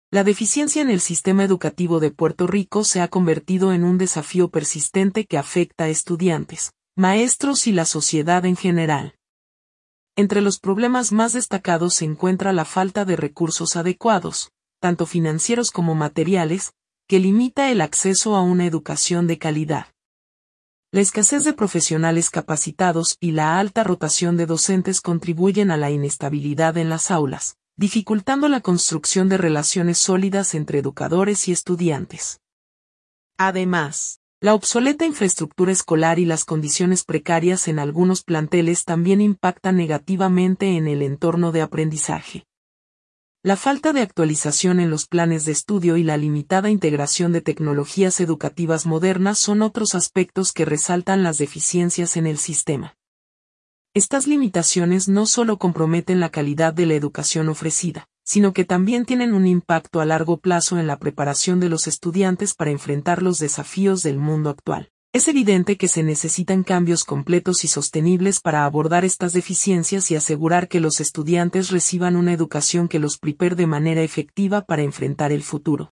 Sistema Educativo de Puerto Rico - Testimonio de un maestro